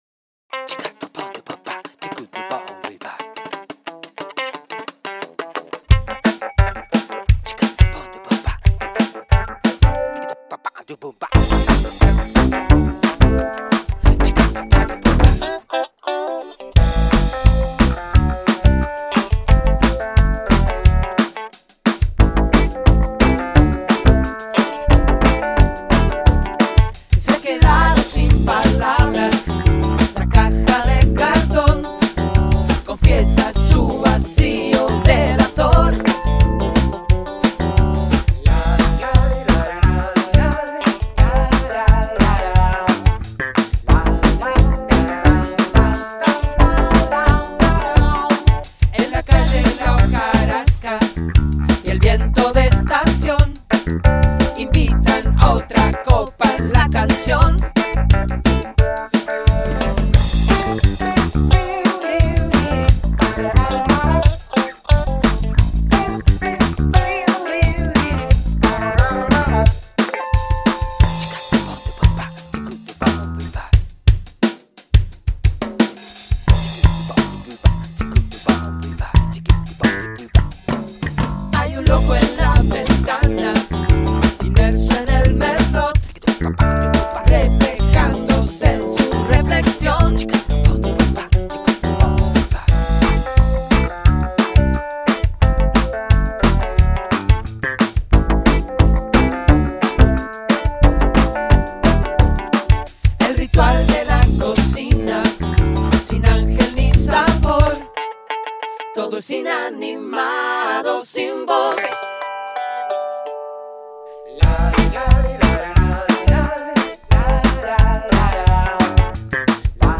El músico mendocino
voces
batería
bajo